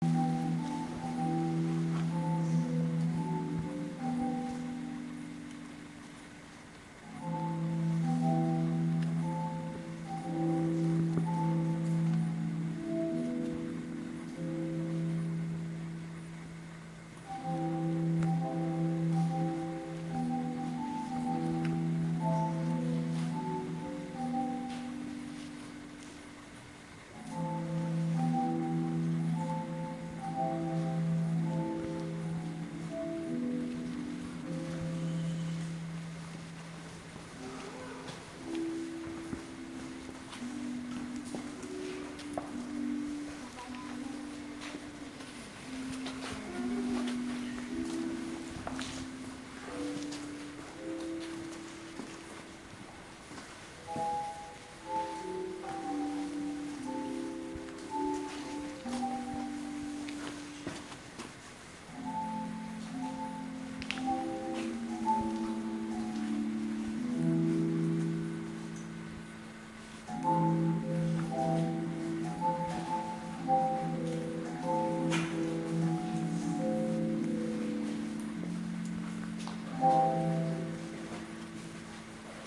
World’s only Stalacpipe Organ. Rubber mallet on the right strikes the stalactite to hit a note
The Stalacpipe Organ sounds eerily beautiful. The tour guide pressed a button to give us the automated demo (the keys on the organ did not move while it played).
organ-audio.m4a